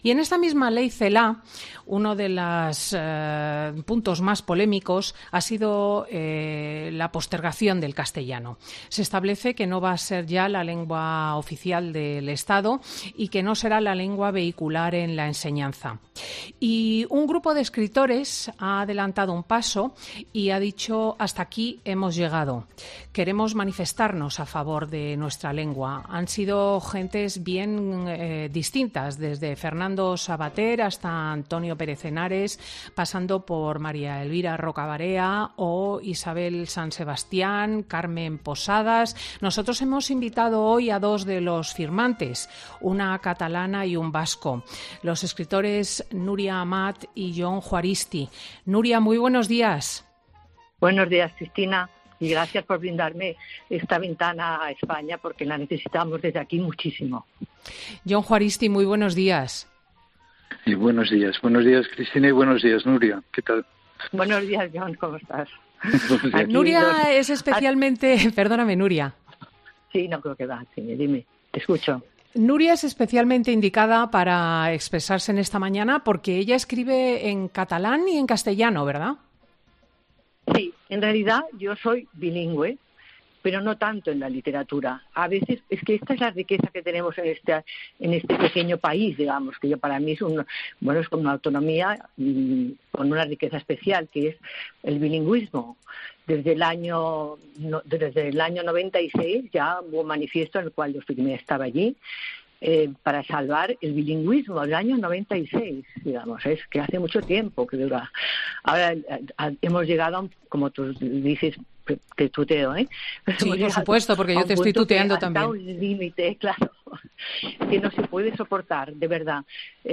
Los escritores Nuria Amat, catalana, o Jon Juaristi, vasco , han criticado en Fin de Semana de COPE la nueva Ley Celáa de Educación en la que se suprime el español como lengua vehicular en los colegios.